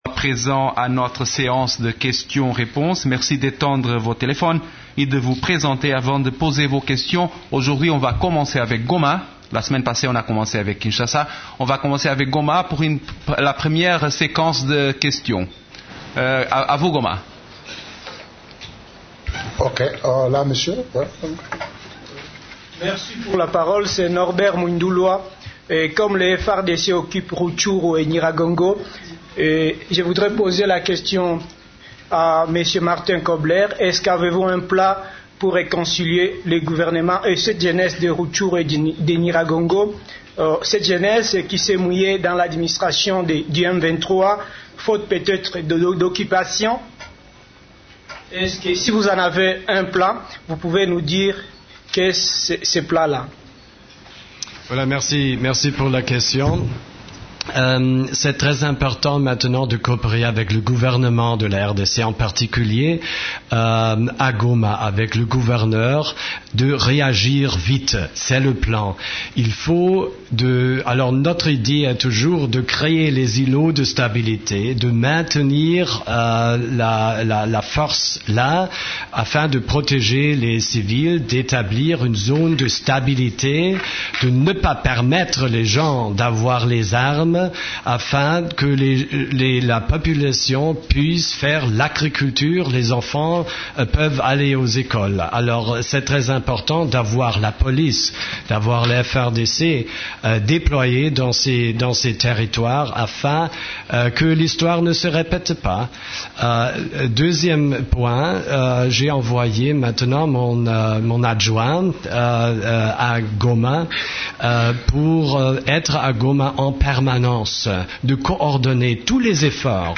La conférence de presse hebdomadaire des Nations unies du jeudi 30 octobre a porté sur les sujets suivants: